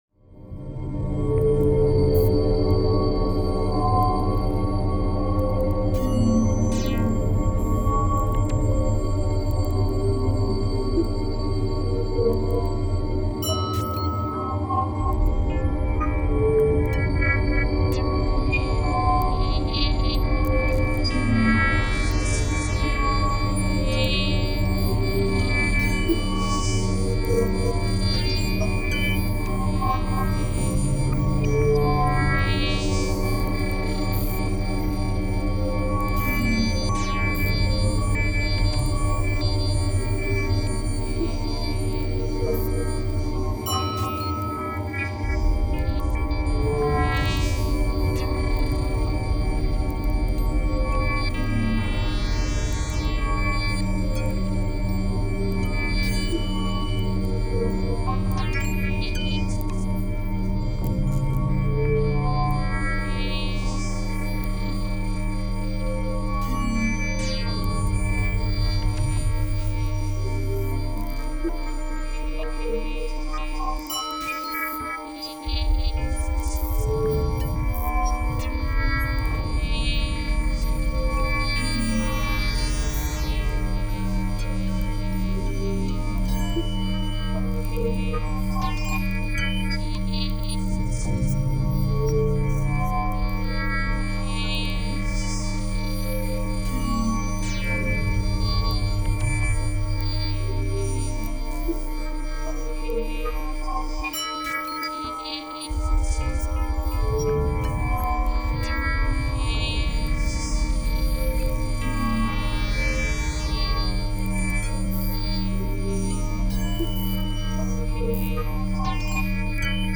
introspective and spatial ambient, of the dark kind